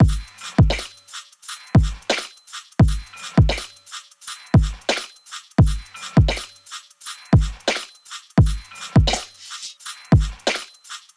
AV_Mixtape_Drums_172bpm
AV_Mixtape_Drums_172bpm.wav